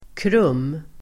Ladda ner uttalet
Uttal: [krum:]